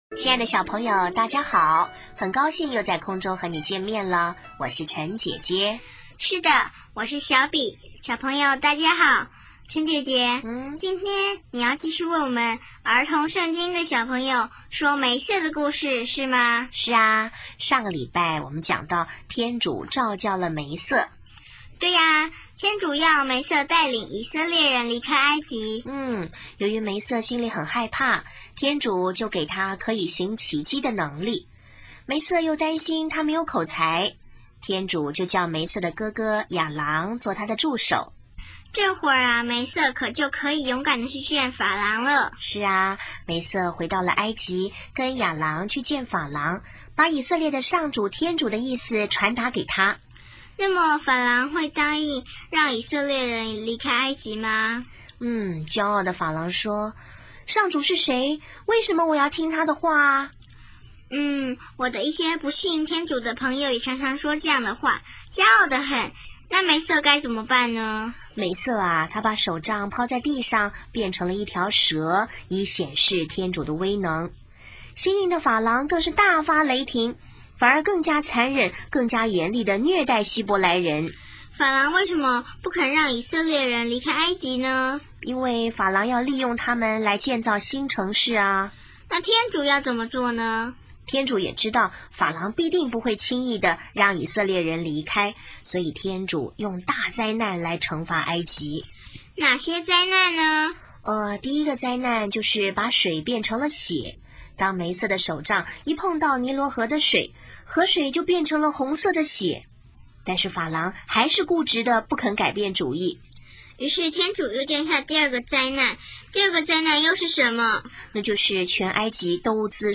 【儿童圣经故事】15|梅瑟(二)蒙受天主召叫